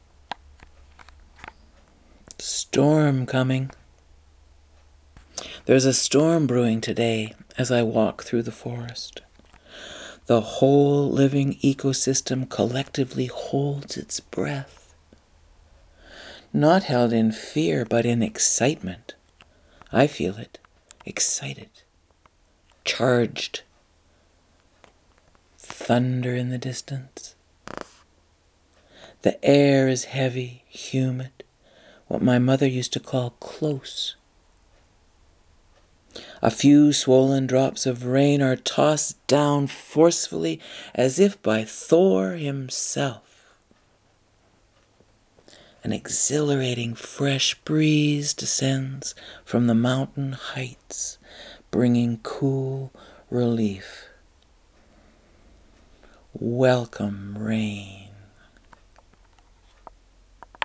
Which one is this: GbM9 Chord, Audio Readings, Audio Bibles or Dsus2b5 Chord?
Audio Readings